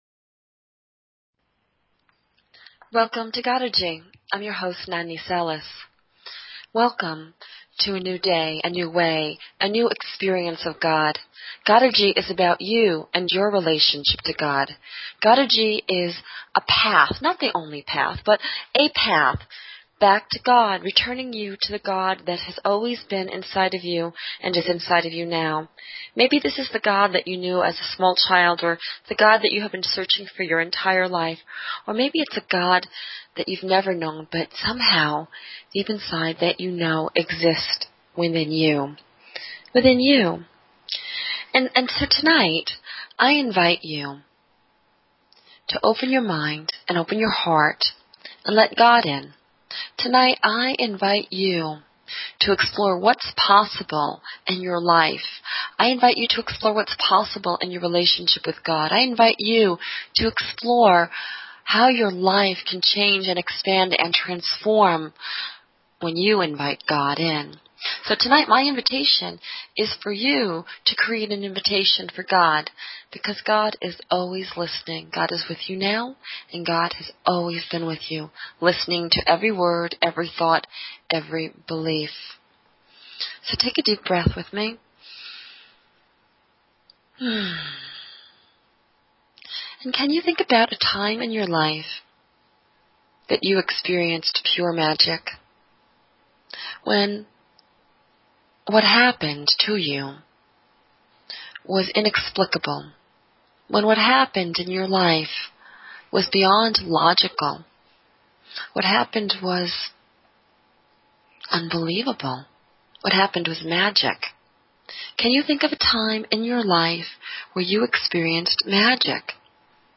Talk Show Episode, Audio Podcast, Godergy and Courtesy of BBS Radio on , show guests , about , categorized as